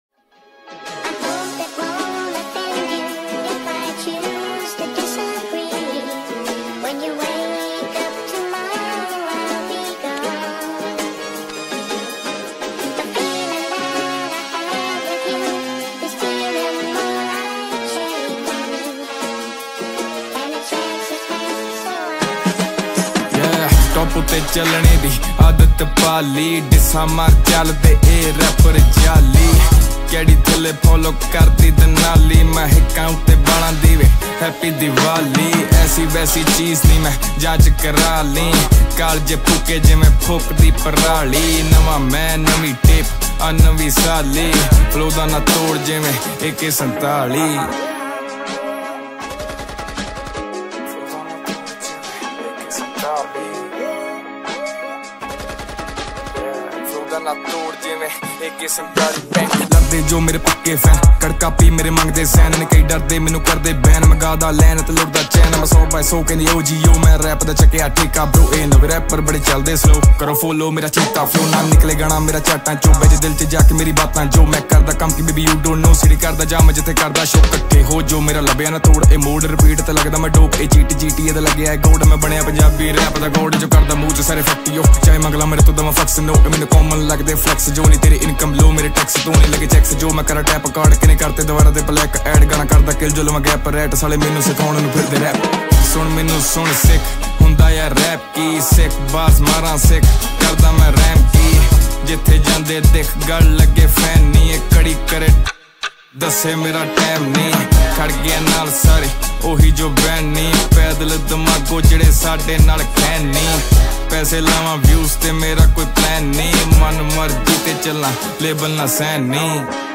Category: New Indian Pop Mp3 Songs